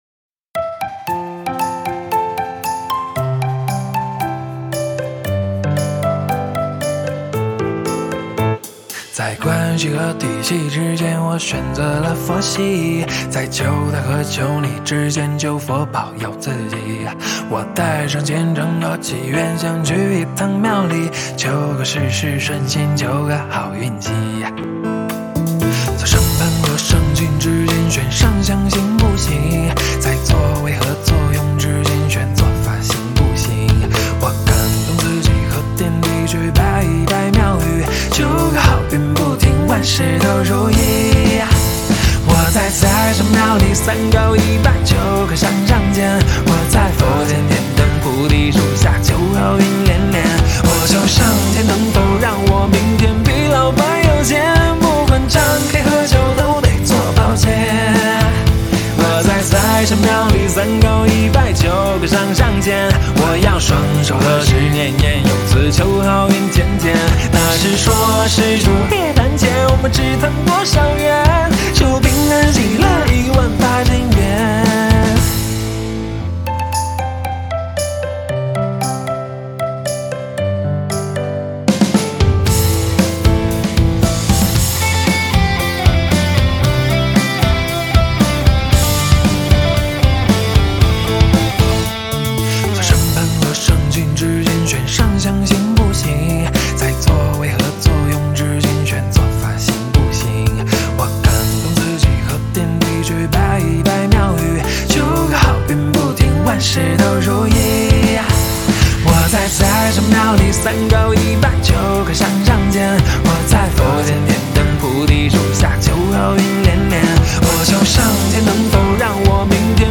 谱内音轨：架子鼓